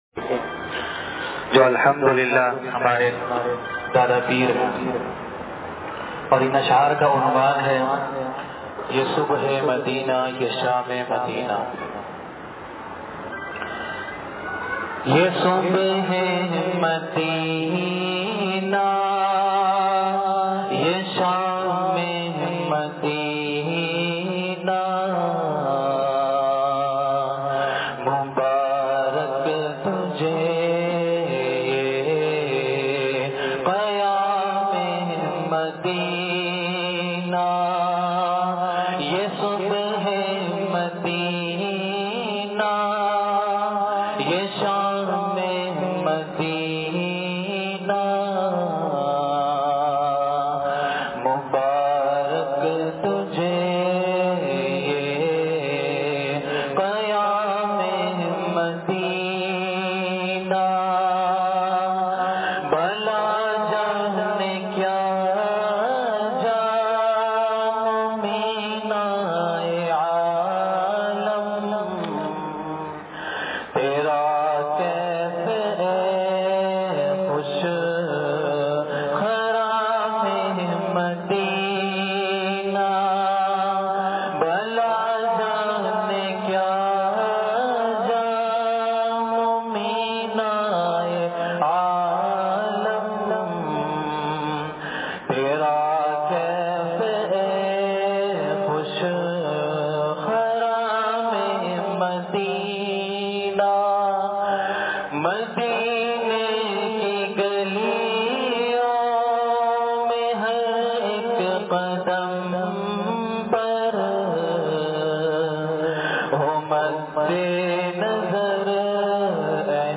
ختم قرآن کے موقع پر انصاریہ مسجد لانڈھی میں خصوصی بیان